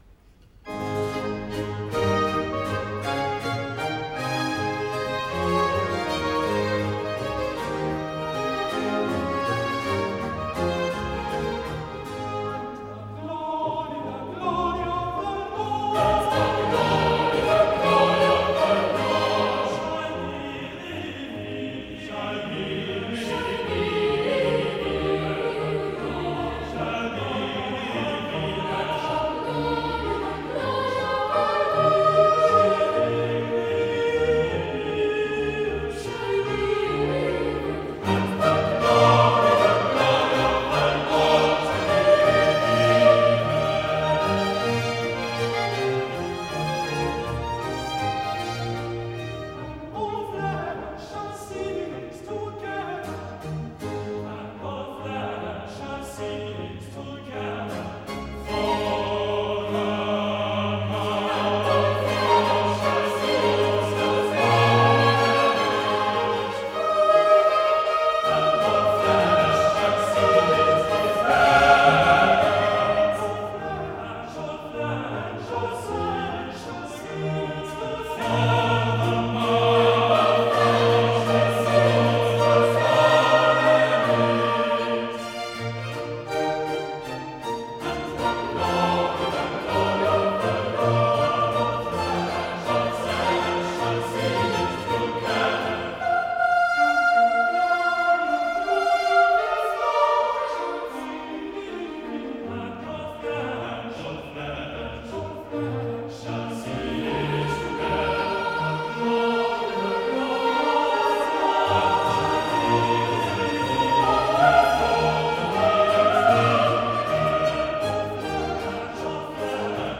Chours